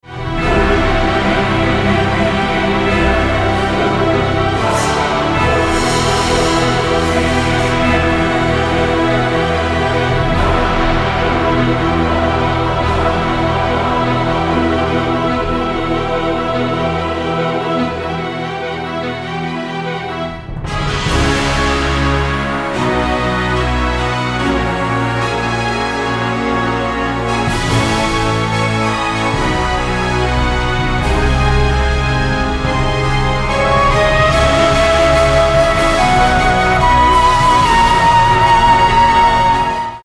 ソロフルート